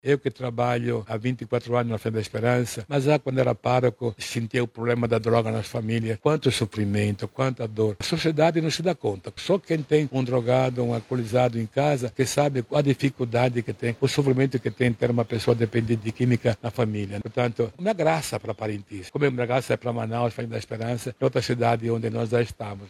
Dom Mário Pasqualotto destaca, ainda, a importância da expansão do Projeto.
SONORA-2-FAZENDA-ESPERANCA-PARINTINS-.mp3